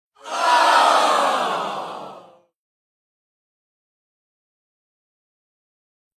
Crowd Aww